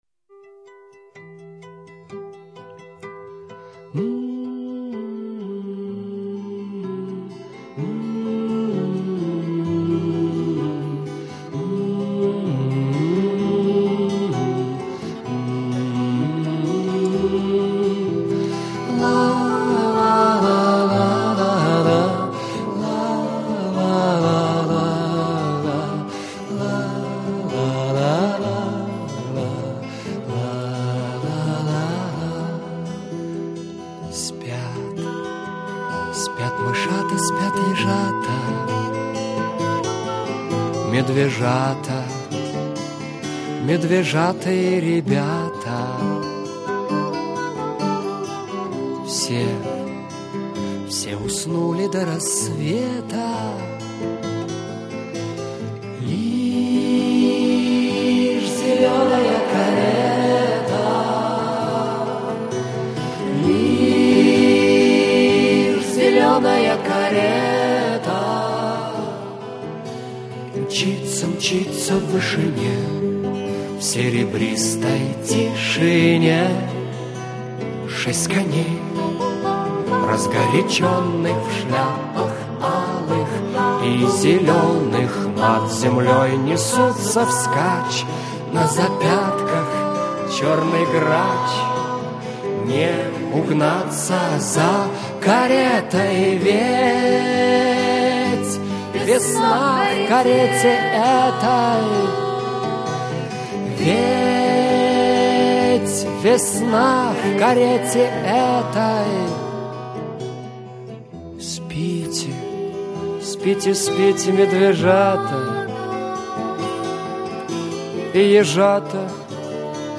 исполнение